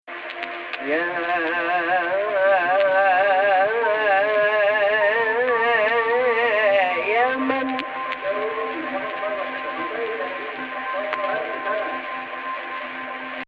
Not to speak of the obvious vocal power.
Actual Key: Rast on F